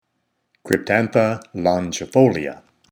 Pronunciation/Pronunciación:
Cryp-tán-tha lon-gi-fò-li-a